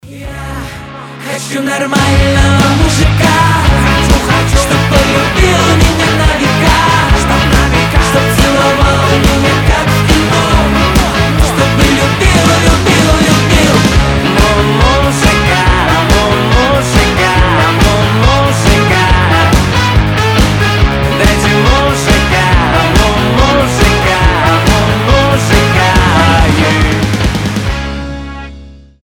• Качество: 320, Stereo
веселые
дуэт
труба